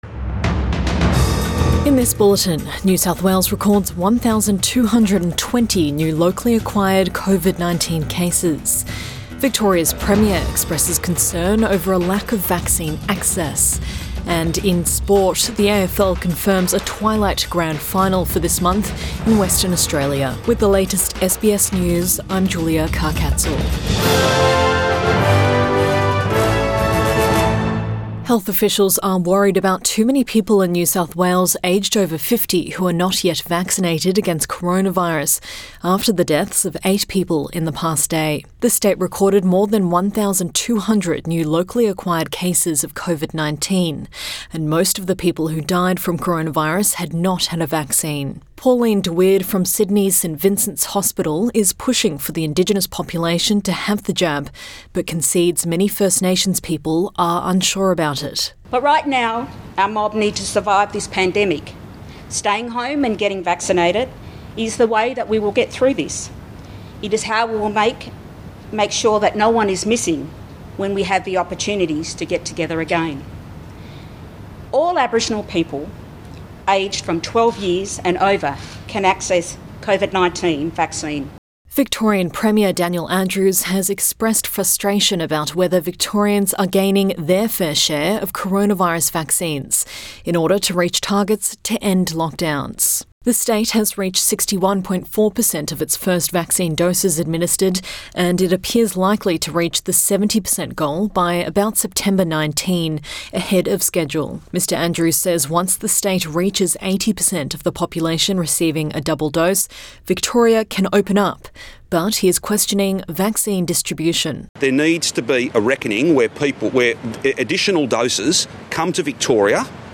PM bulletin 7 September 2021